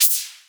edm-perc-37.wav